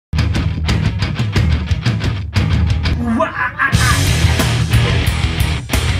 Play, download and share yoda oh wah ah ah ah original sound button!!!!
yoda-oh-wah-ah-ah-ah.mp3